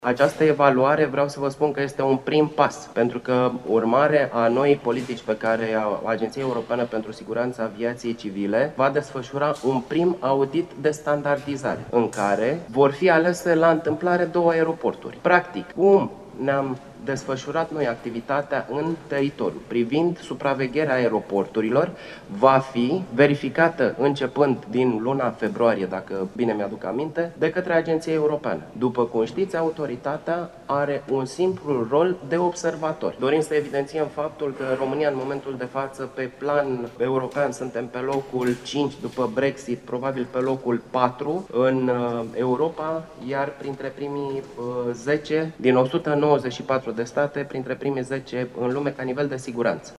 Din 2019, agenţia europeană va face evaluări privind singuranţa aviaţiei civile din România, a declarat directorul general al Autorităţii Aeronautice Române, Armand Petrescu.
Acesta a participat la lucrările Adunării Generale a Asociaţiei Aeroporturilor din România.